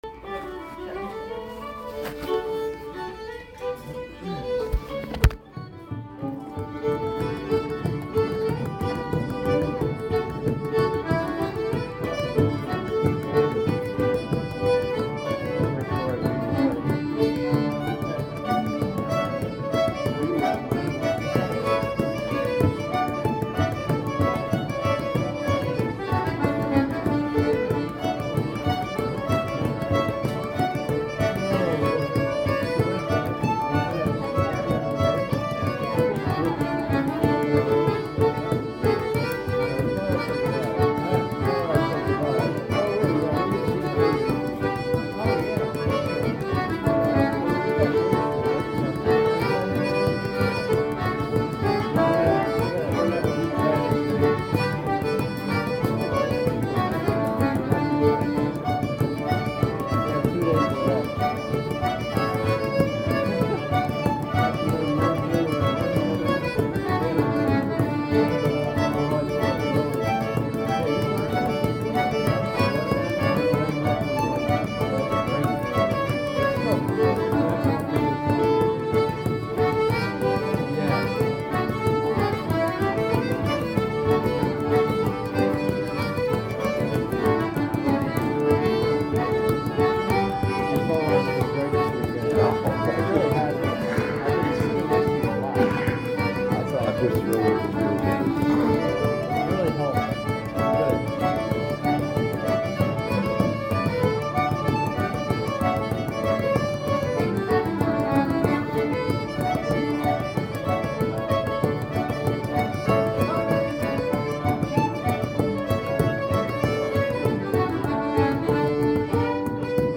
North American Comhaltas convention in Orlando
Many sessions started out small each night, in an one of the alcoves outside the hotel.
And the music continued well after midnight. At times there were about 50 musicians.